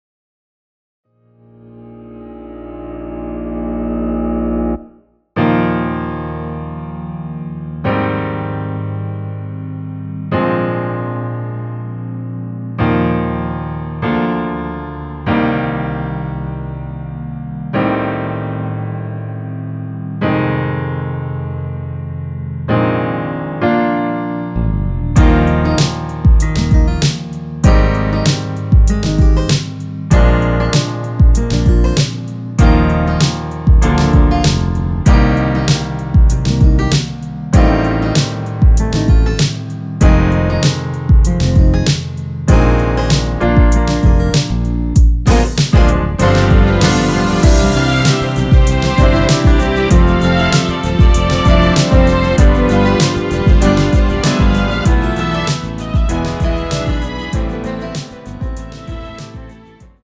원키에서(-7)내린 MR 입니다.(미리듣기 참조)
Eb
앞부분30초, 뒷부분30초씩 편집해서 올려 드리고 있습니다.
중간에 음이 끈어지고 다시 나오는 이유는